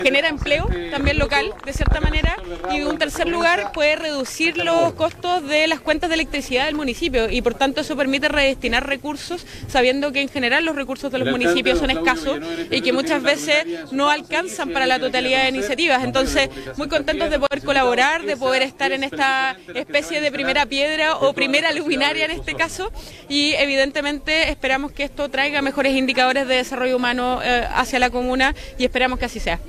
Este martes, se dio inicio al recambio de 15.872 luminarias en el radio urbano de Osorno, en un acto celebrado en la Plaza España de Rahue Alto.
Por otra parte, la Subsecretaria agregó que esta iniciativa no solo abrirá espacios laborales en la comuna de Osorno, sino que también contribuirá a mejorar la eficiencia energética.